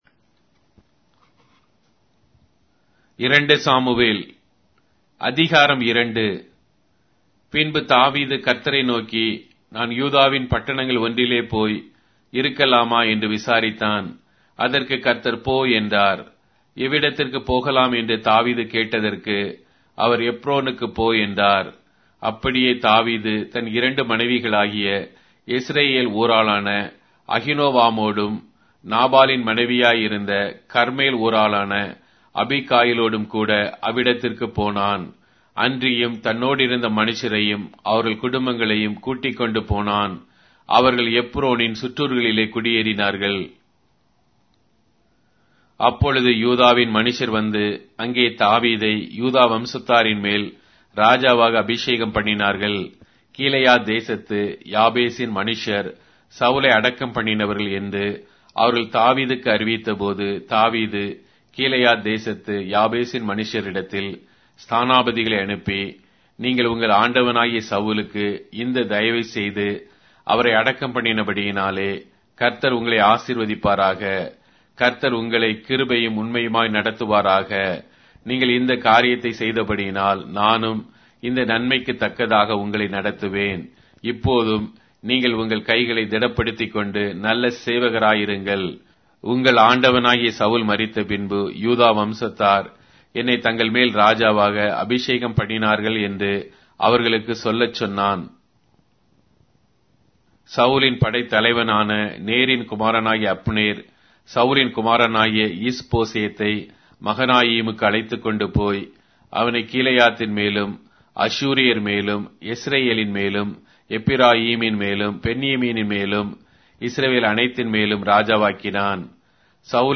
Tamil Audio Bible - 2-Samuel 1 in Akjv bible version